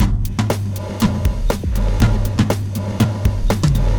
Action Stations (Drums) 120BPM.wav